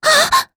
s024_Noraml_Hit.wav